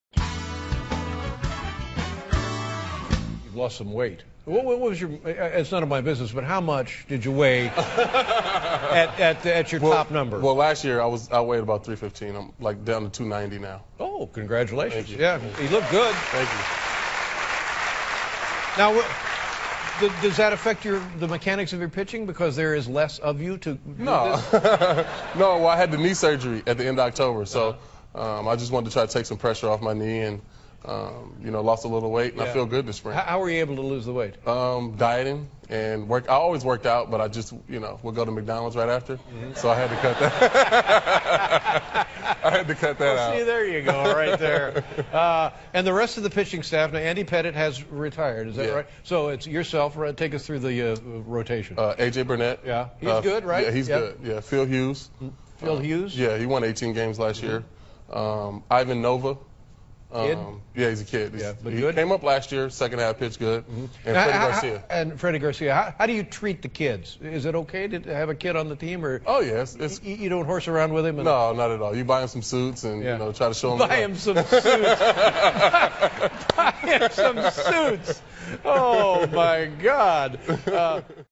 访谈录 2011-04-10&04-12 棒球巨星CC沙巴西亚专访 听力文件下载—在线英语听力室